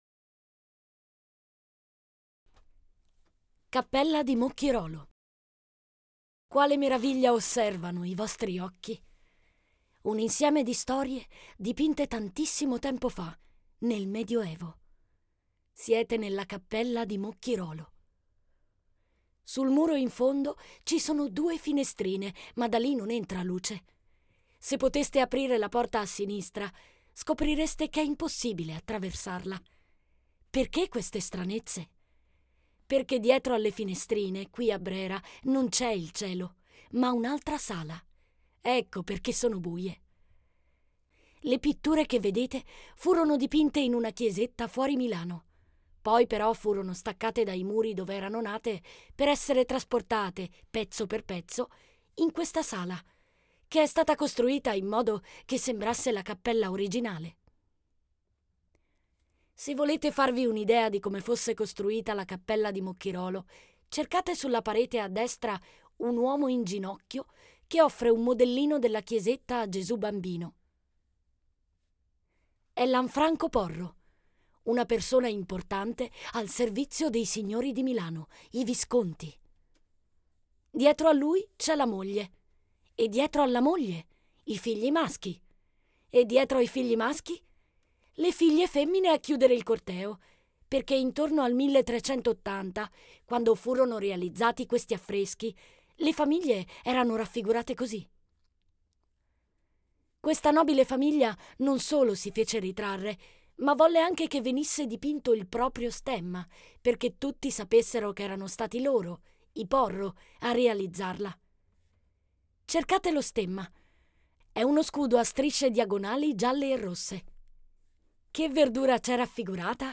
Audioguida "Brera in famiglia"